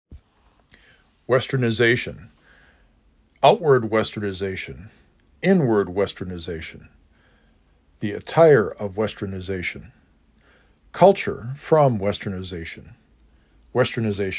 w e · s t er · n i · z A · S ə n
5 Syllables: west-ern-i-ZA-tion
Stressed Syllable: 4